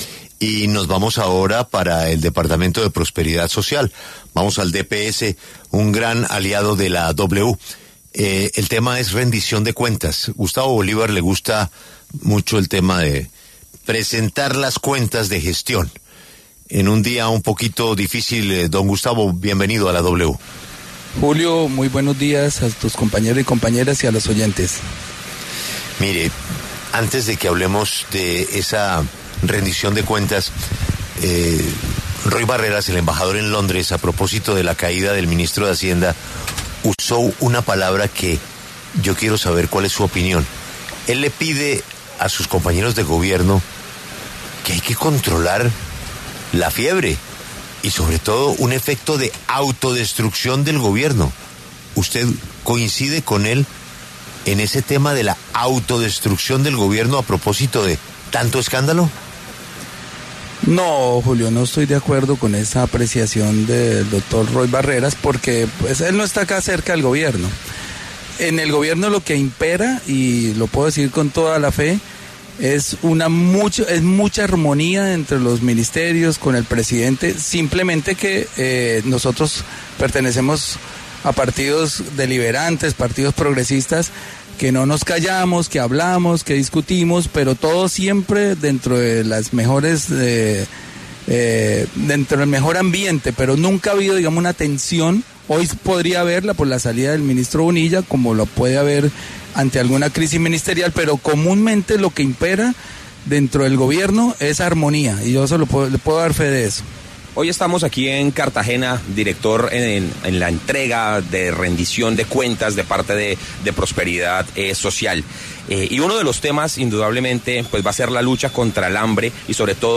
Gustavo Bolívar, director de Prosperidad Social, respondió en La W a la declaración de Roy Barreras sobre una “autodestrucción” al interior del Gobierno.